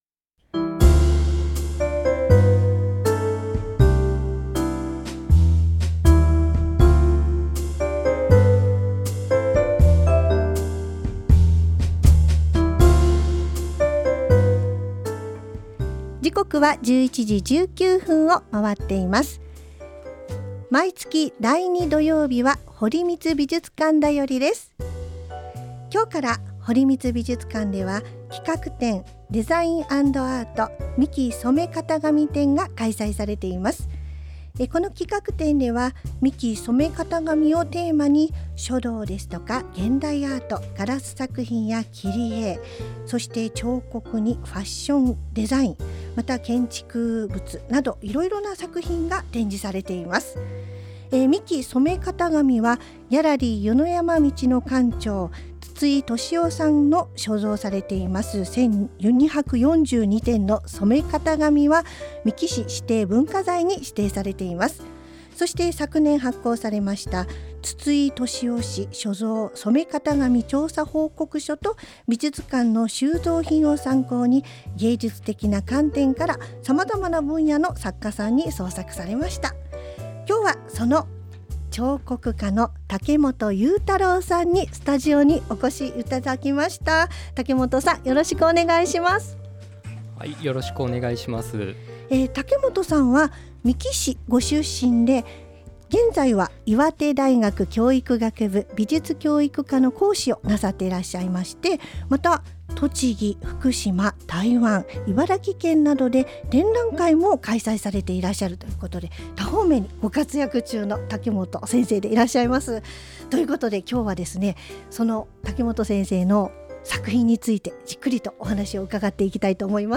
エフエムみっきいに出演しました